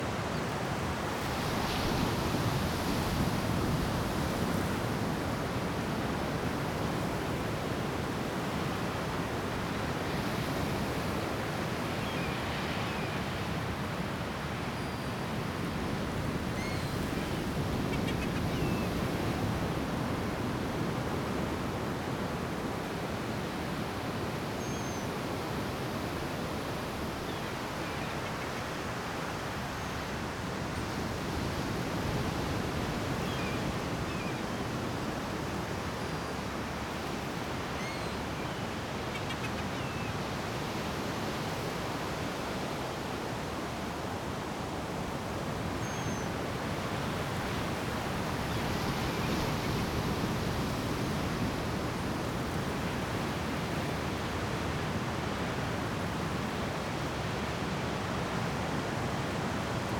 BGS Loops
Beach.wav